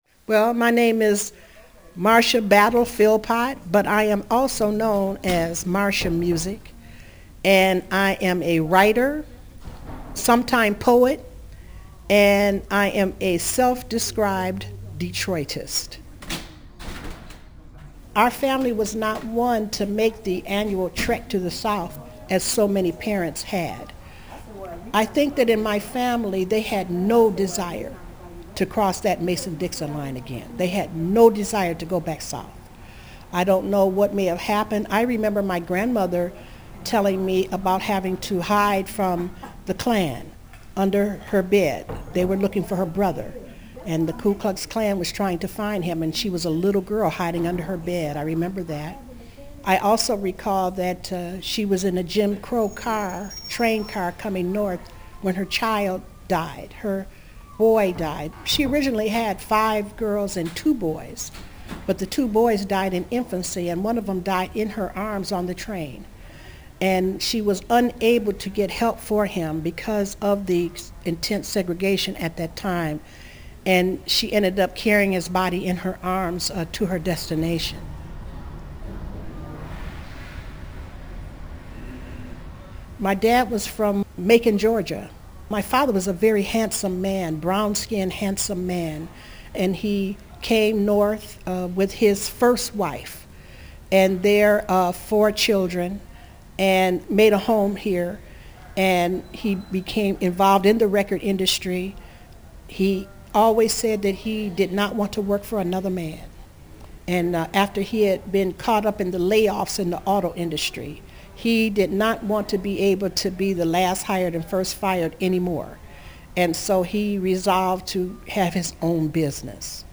Oral histories (literary works)